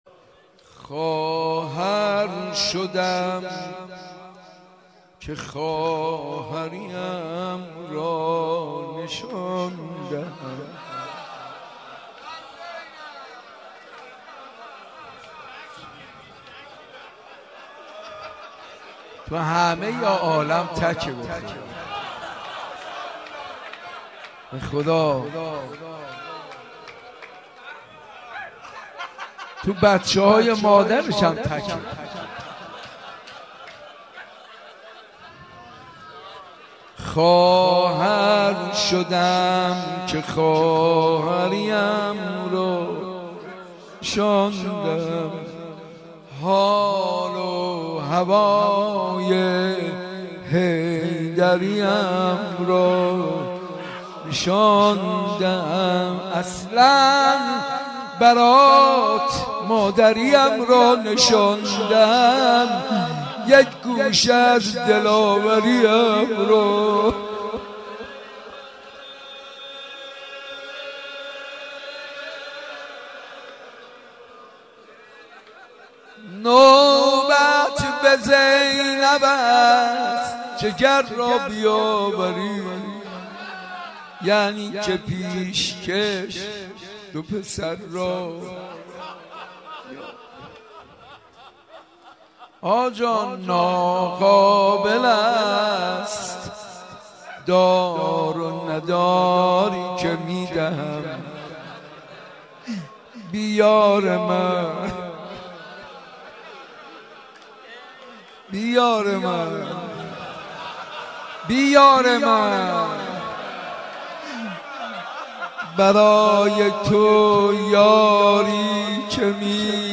خواهر شدم که خواهری اَم را نشان دهم | روضه | طفلان حضرت زینب سلام الله علیها